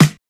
Snare (Rapp Snitch Knishes).wav